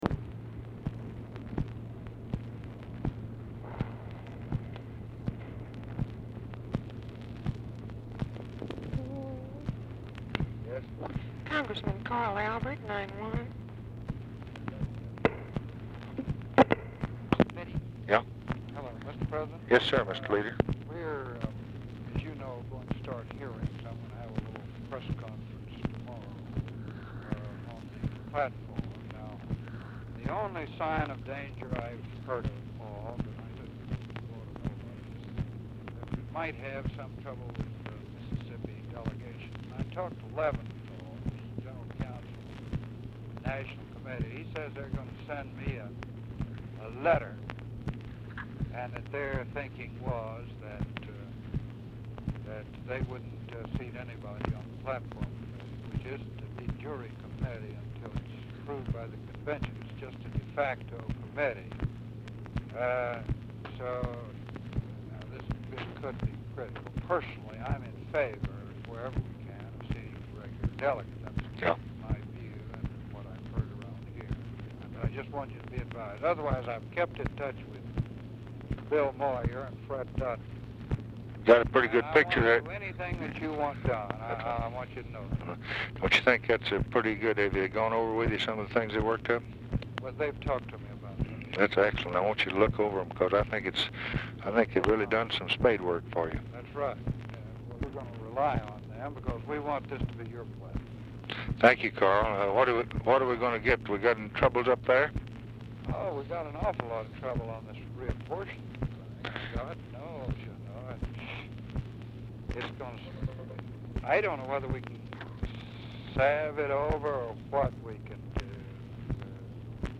ALBERT IS DIFFICULT TO HEAR
Format Dictation belt
Specific Item Type Telephone conversation Subject Civil Rights Congressional Relations Elections Judiciary Legislation National Politics